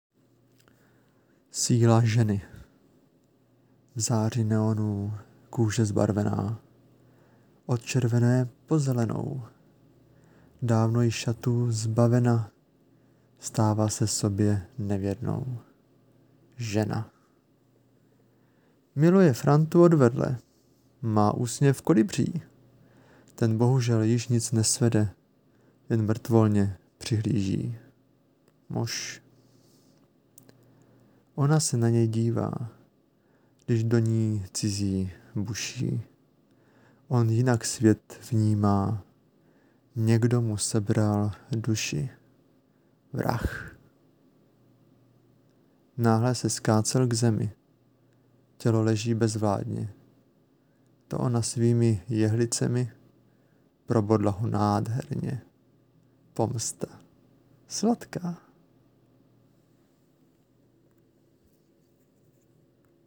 Máš příjemný hlas.